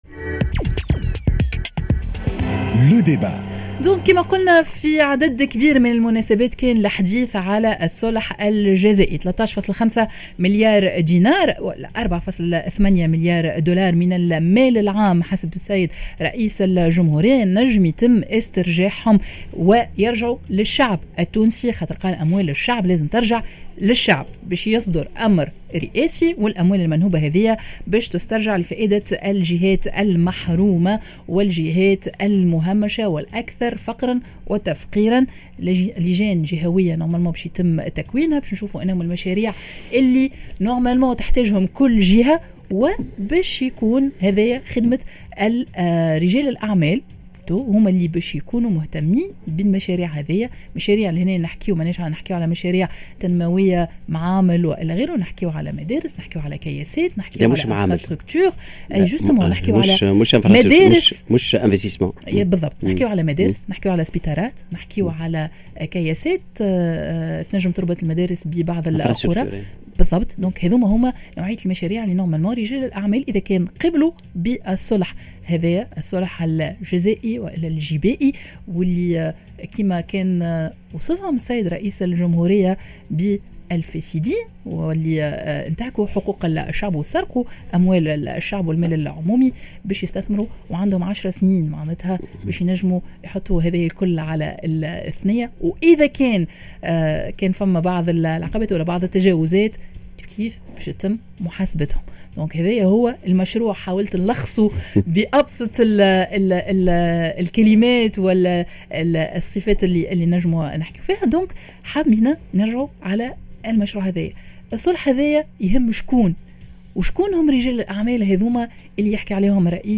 Le débat: شكون لازموا يستثمر في الجهات الفقيرة و المهمّشة الدولة و الاّ رجال الأعمال؟